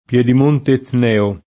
pLHdim1nte etn$o] (Sic.); e d’un ex comune: Piedimonte del Calvario [pLHdim1nte del kalv#rLo] (V. G.)